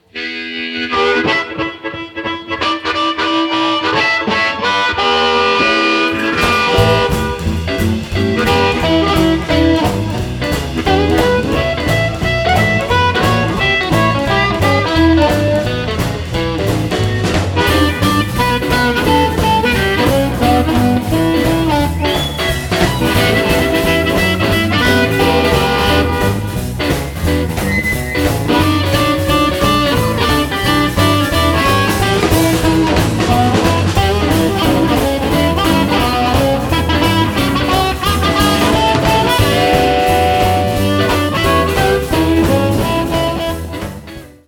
recorded live at Moe's Alley in Santa Cruz, California